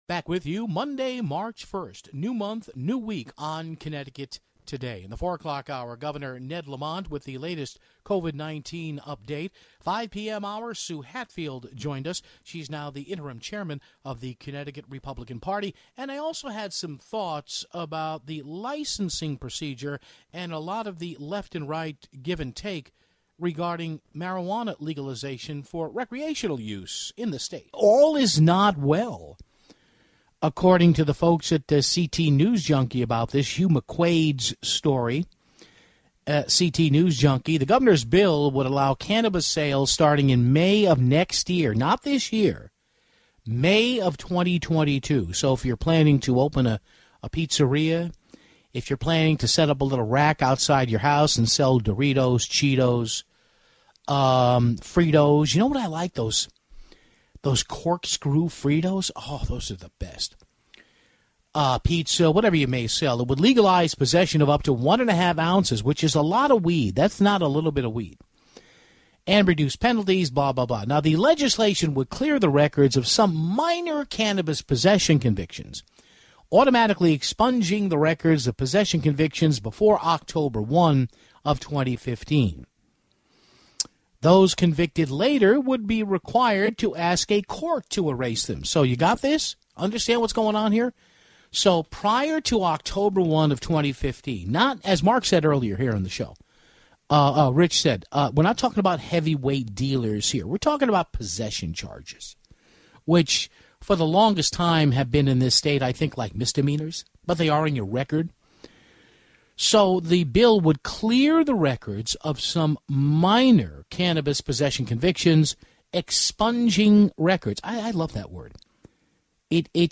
Next, we carried Governor Ned Lamont’s latest meeting with the media, and it sounded like some coronavirus restrictions could start to be lifted soon (9:00).